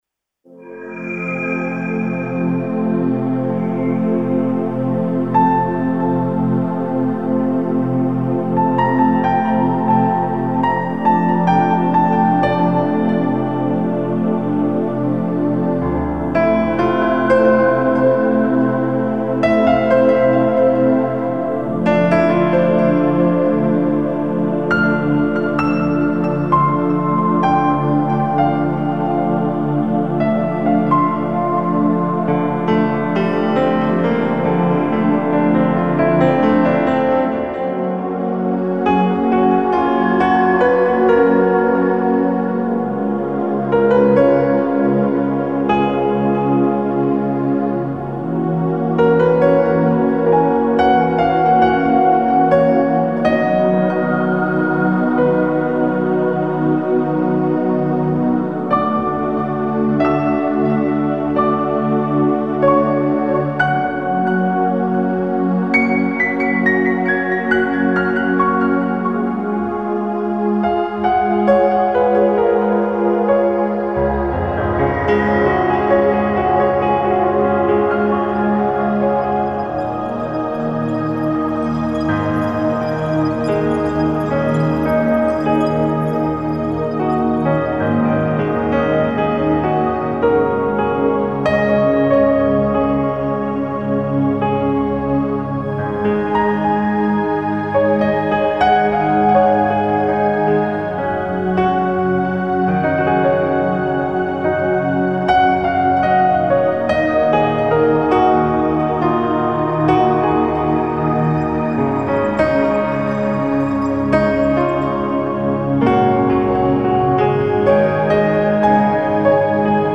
幻想的なシンセパッドと、アンビエントなピアノサウンドが特徴の楽曲です。